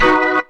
B3 BMAJ 2.wav